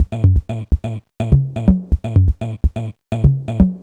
cch_jack_percussion_loop_air_125.wav